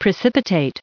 613_precipitate.ogg